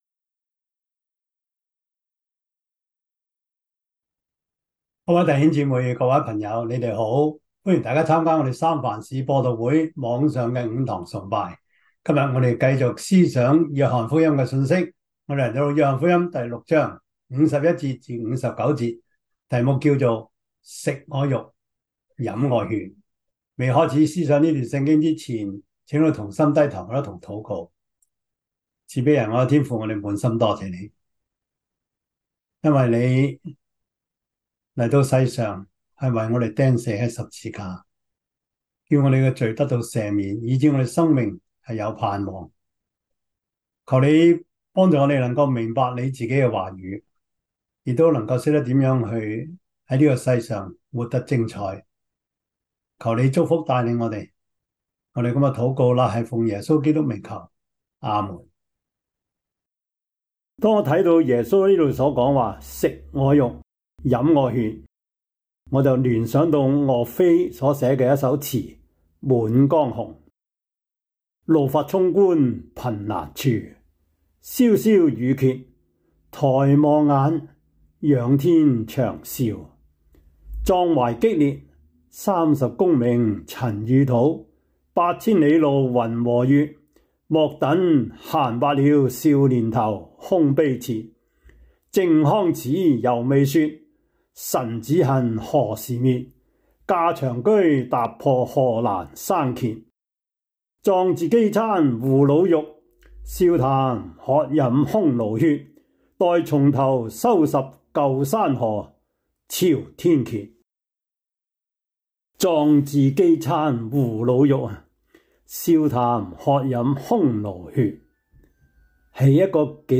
約翰福音 6:51-59 Service Type: 主日崇拜 約翰福音 6:51-59 Chinese Union Version
Topics: 主日證道 « 會幕是如何建成的?